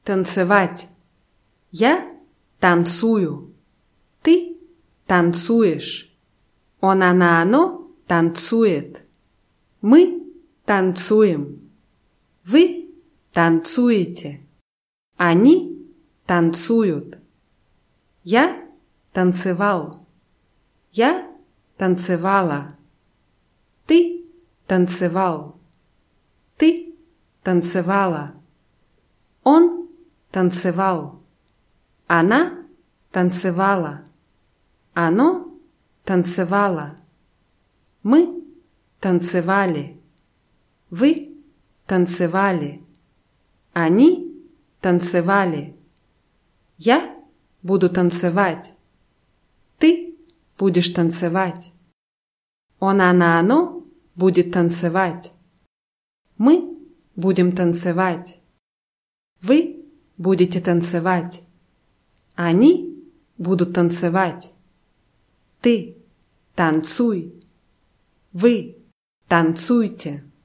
танцевать [tantsywátʲ]